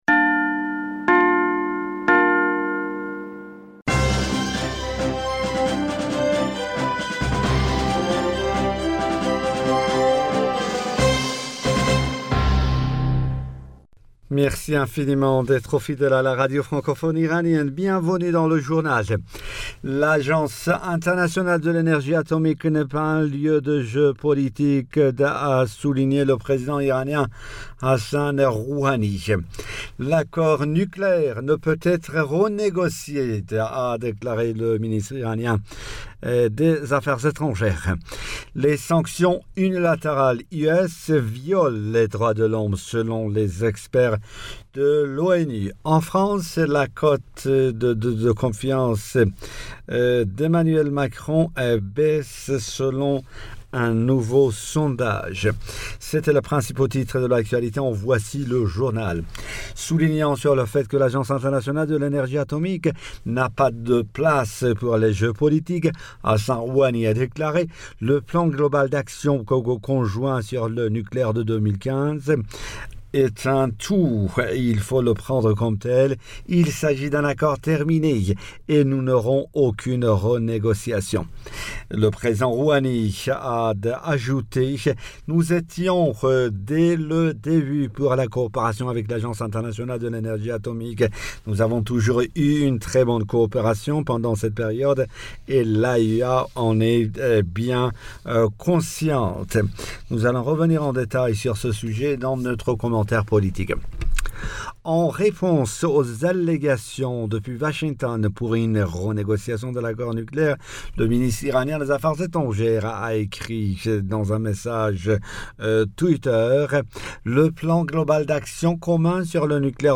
Bulletin d'informationd u 05 Mars 2021